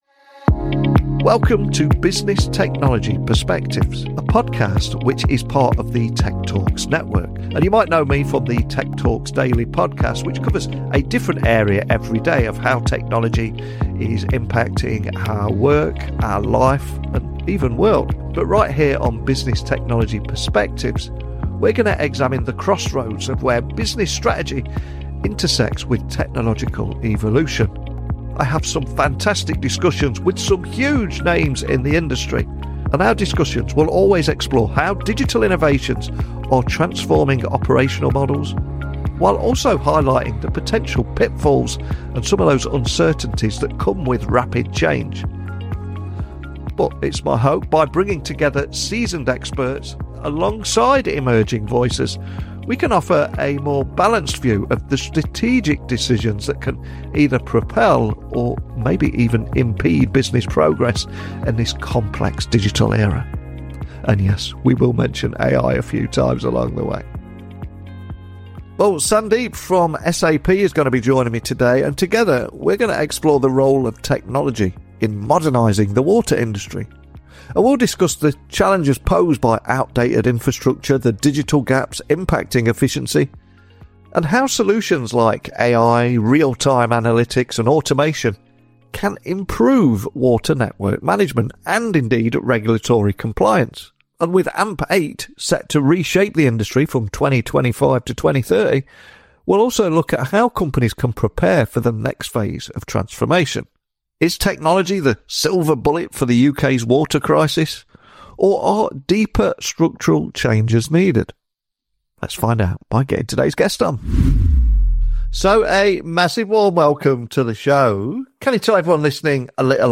Recorded remotely but rooted in shared local ties—we discovered we’re both based in the West Midlands—this discussion covers the real pressures weighing on the water industry right now. From cost increases and regulatory demands to a public that’s watching more closely than ever, the sector is under intense scrutiny.